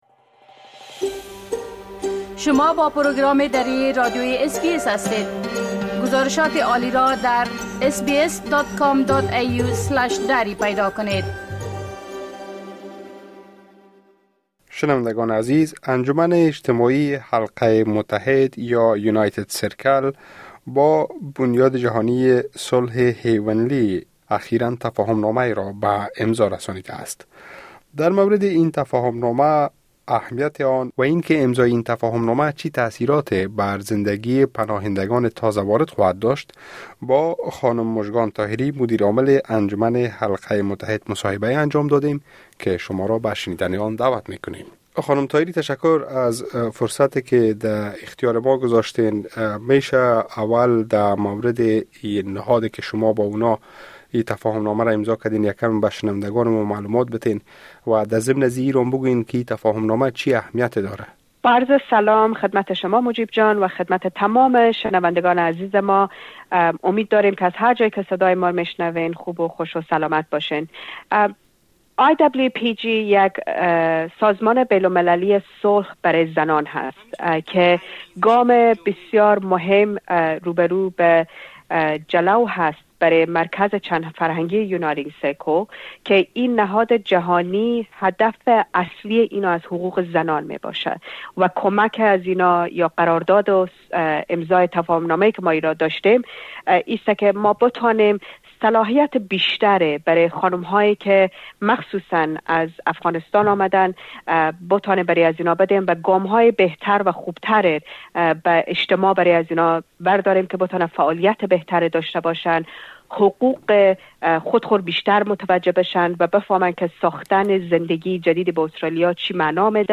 گفتگوی انجام دادیم.